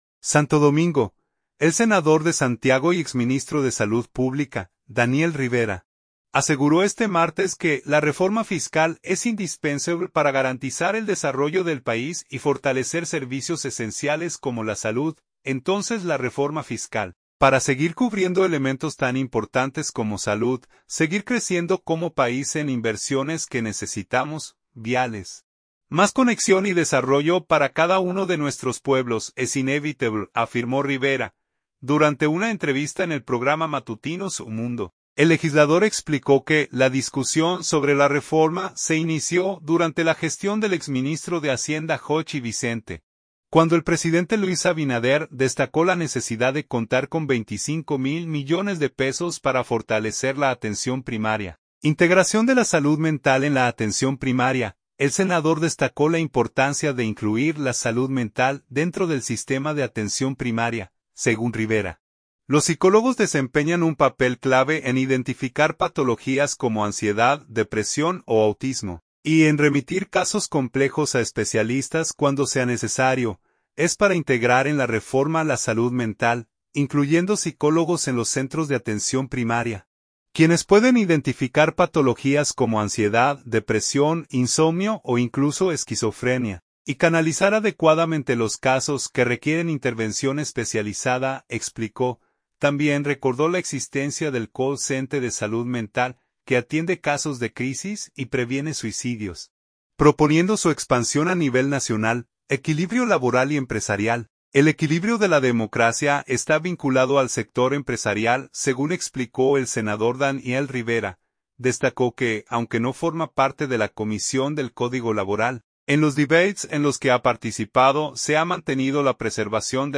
Durante una entrevista en el programa Matutino Su Mundo, el legislador explicó que la discusión sobre la reforma se inició durante la gestión del exministro de Hacienda Jochi Vicente, cuando el presidente Luis Abinader destacó la necesidad de contar con 25 mil millones de pesos para fortalecer la atención primaria.